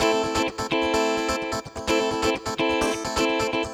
VEH3 Electric Guitar Kit 1 128BPM
VEH3 Electric Guitar Kit 1 - 2 A min.wav